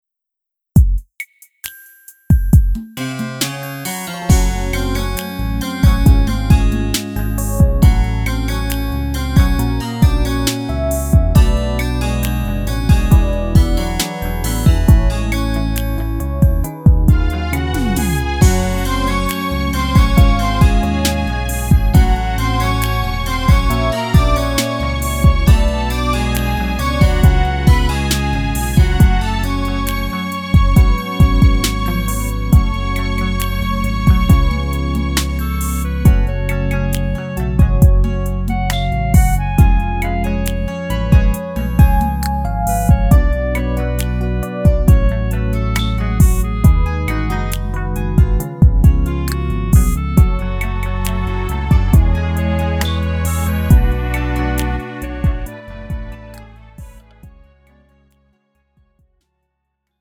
음정 -1키 3:53
장르 가요 구분 Lite MR